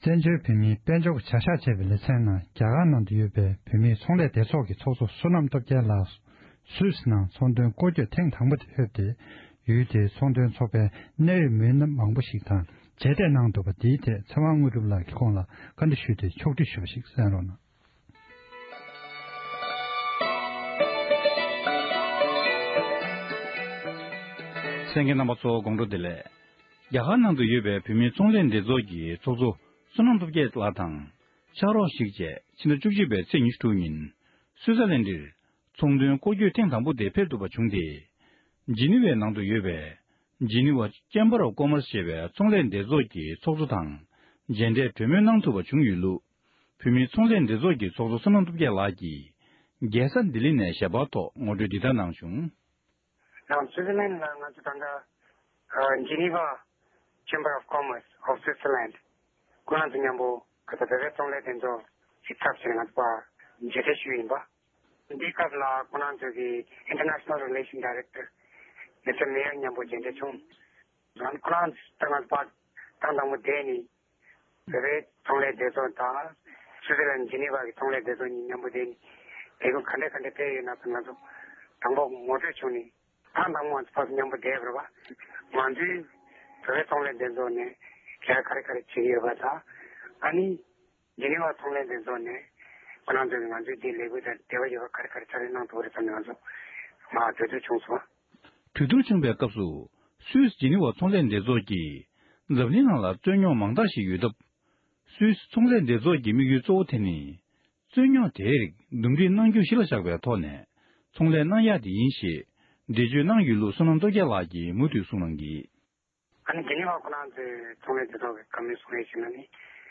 ཁོང་ལ་བཀའ་འདྲི་ཞུས་ཏེ་ཕྱོགས་བསྒྲིགས་ཞུས་པ་ཞིག་གསན་རོགས༎